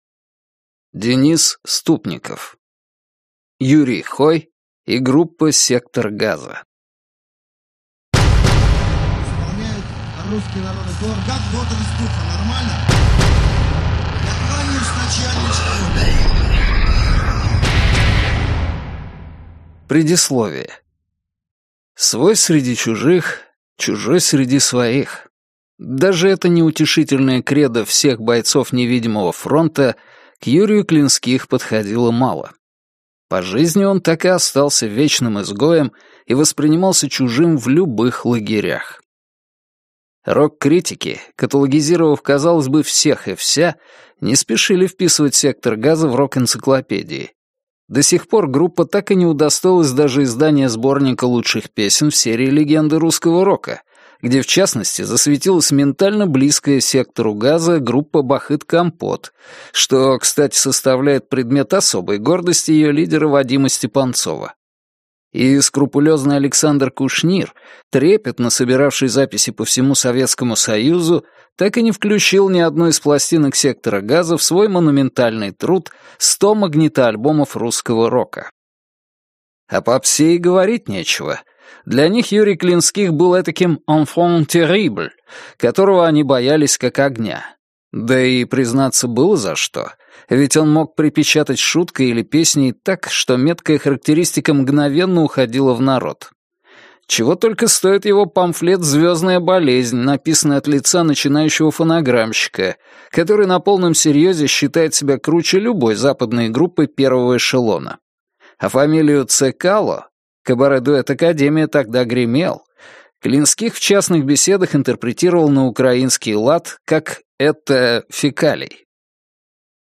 Аудиокнига Юрий Хой и группа «Сектор Газа» | Библиотека аудиокниг